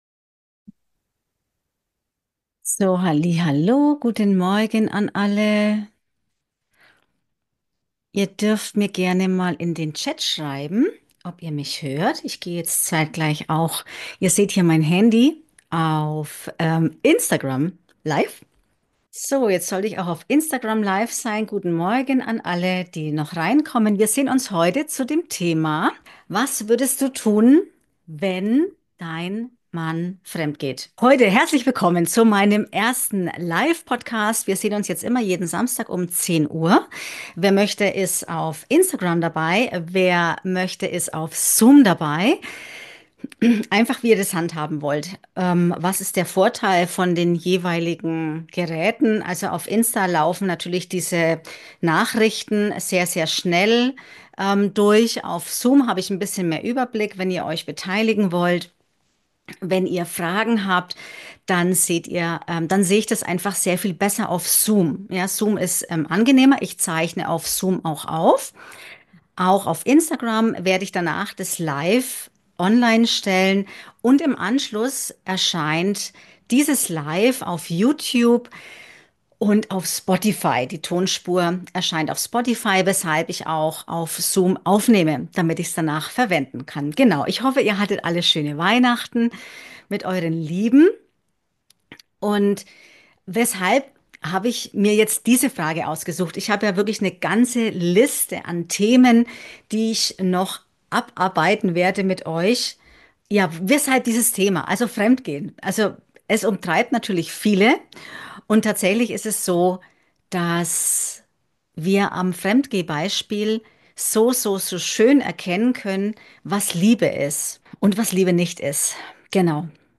Live-Podcast ~ LIEBESKUNST Podcast